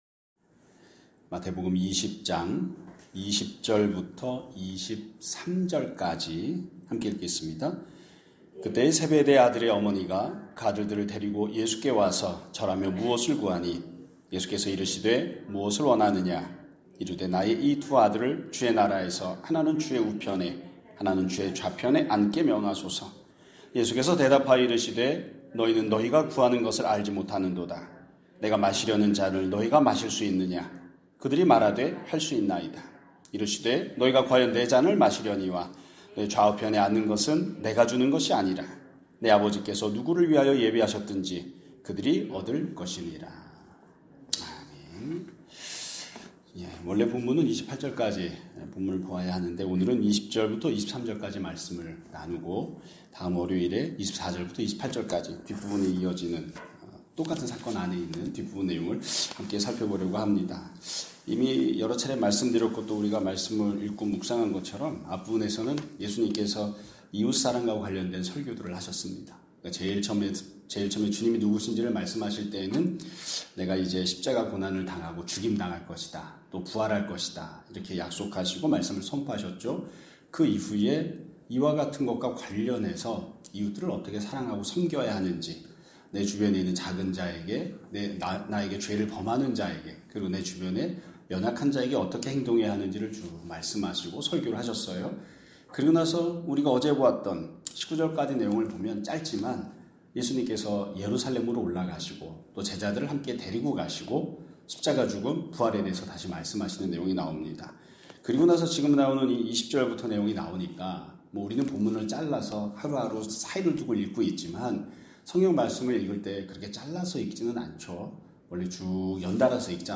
2026년 1월 16일 (금요일) <아침예배> 설교입니다.